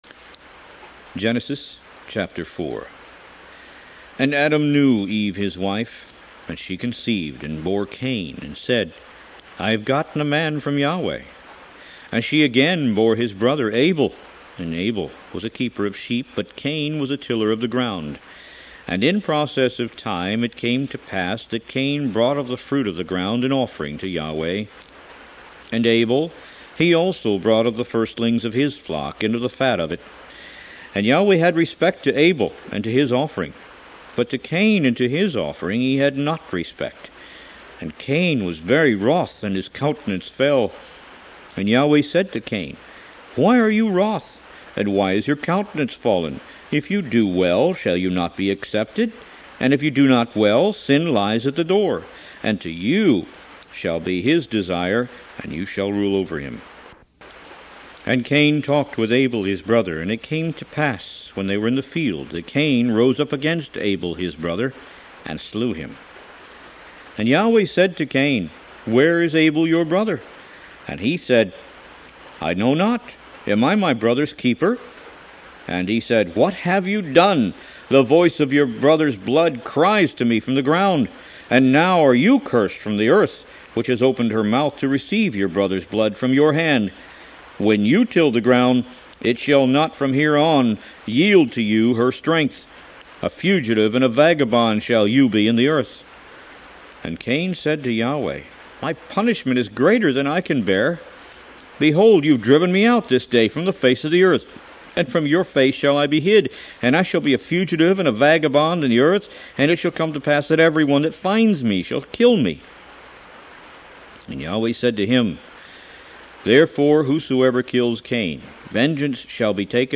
Root > BOOKS > Biblical (Books) > Audio Bibles > Tanakh - Jewish Bible - Audiobook > 01 Genesis